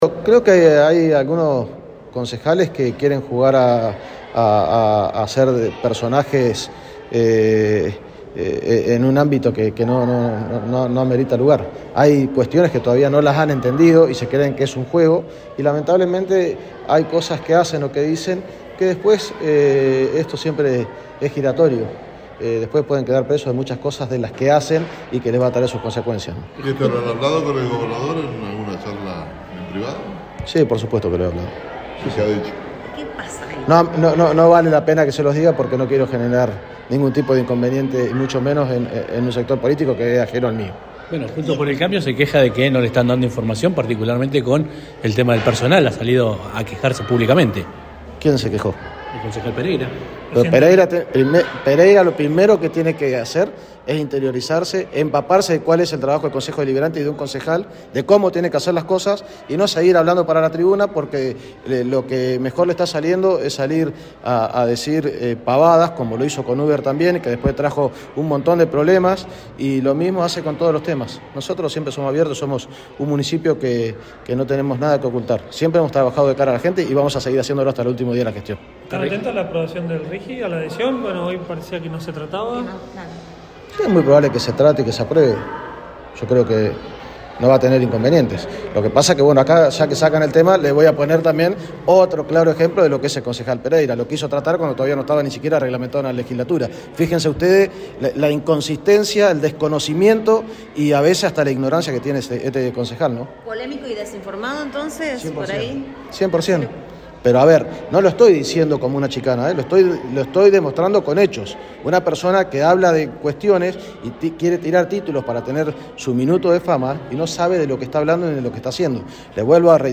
En un tono crítico, Sastre afirmó: “Creo que hay algunos concejales que quieren jugar a ser personajes en un ámbito que no amerita lugar. Hay cuestiones que todavía no las han entendido y se creen que es un juego”.